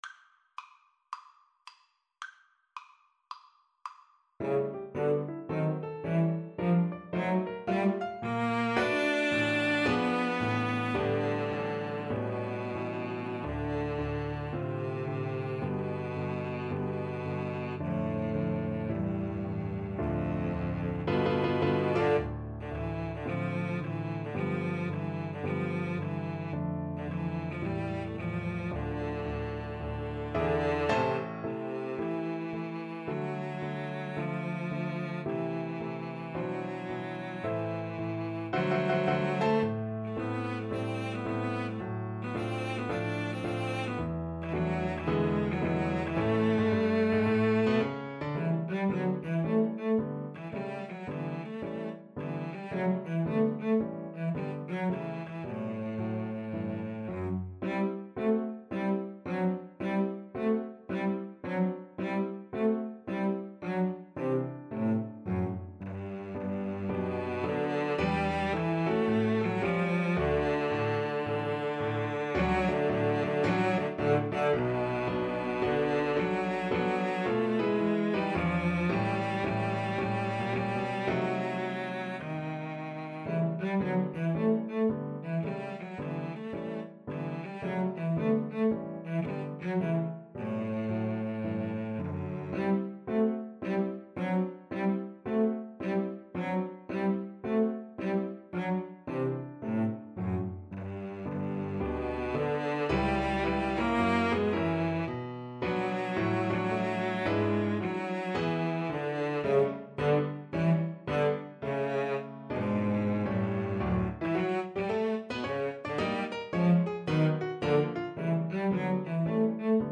Moderato =110 swung